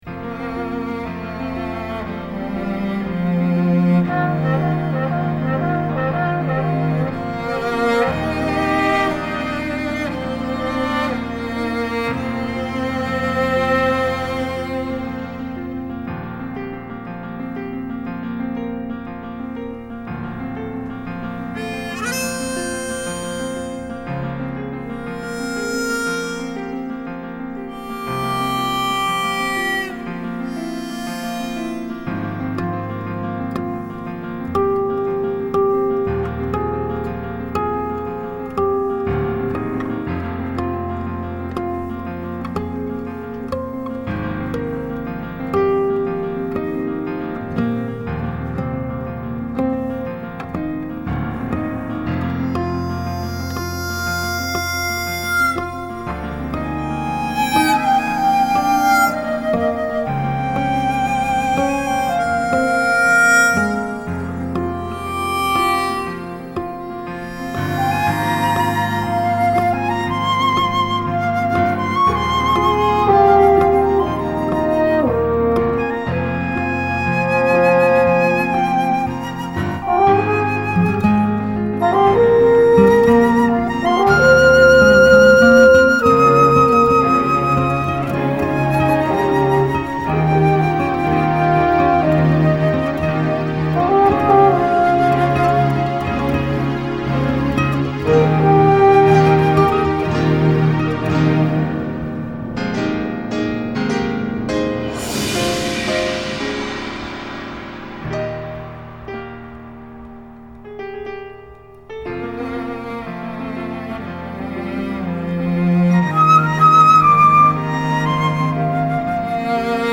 Thematic Film Music